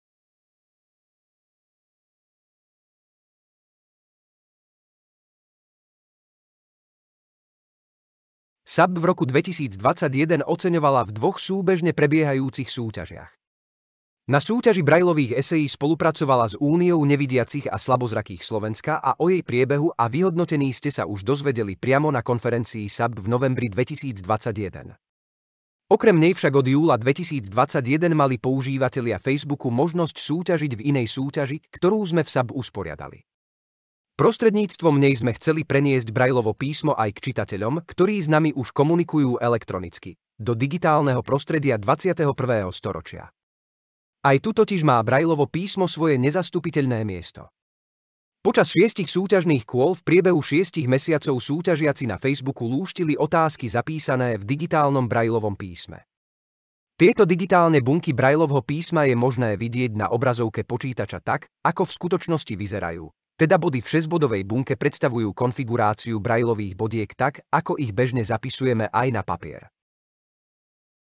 Načítavateľ 1: